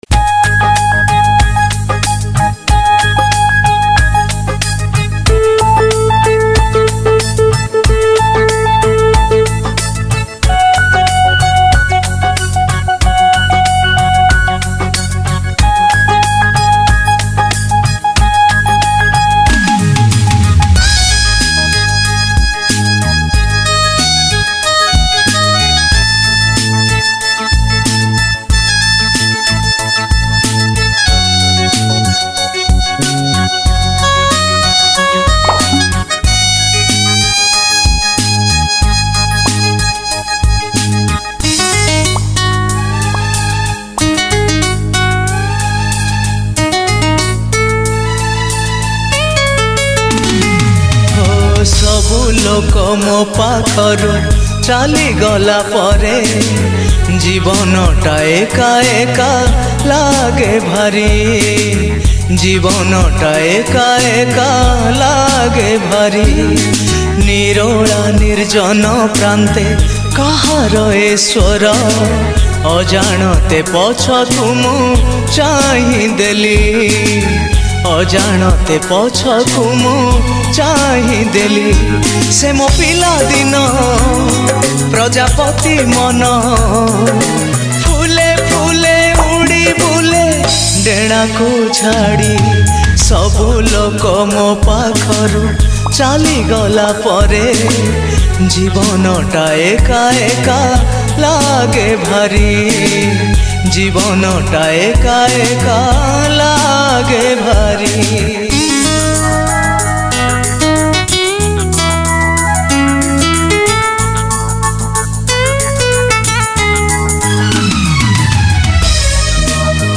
Beautiful Odia Sad Song